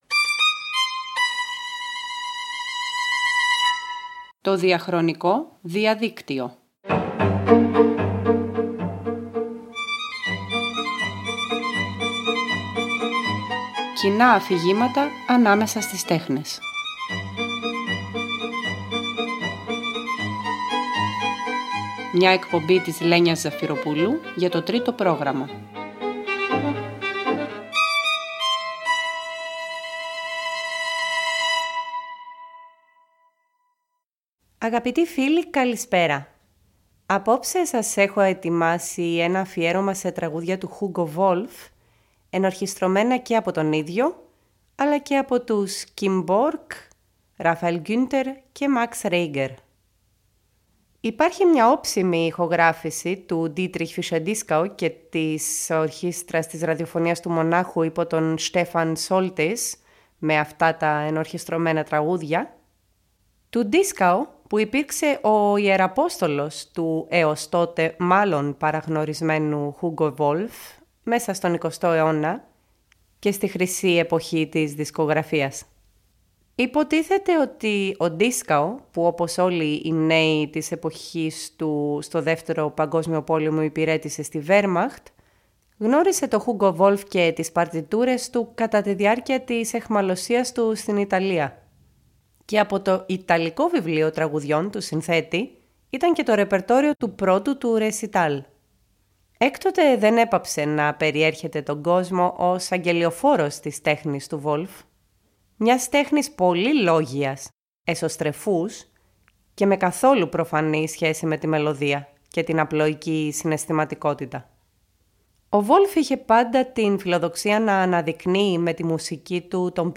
Μία εκπομπή για τον μοναχικό ιεροφάντη της τραγουδισμένης ποίησης.
Εφευρίσκοντας ένα προσωπικό, αναγνωρίσιμο είδος μουσικής απαγγελίας και θυσιάζοντας συχνά την απλή μελωδικότητα, έπλασε τα τραγούδια του σαν αξεδιάλυτη αριστοτεχνική χημική ένωση μουσικής και στίχων. Ο ίδιος αλλά και δυο τρεις άλλοι ενορχηστρωτές, μεταξύ τους και ο Max Reger, πειραματίστηκαν με τα πλούσια χρώματα της συμφωνικής ορχήστρας πάνω στα φαινομενικά στεγνά και υπερλόγια τραγούδια του.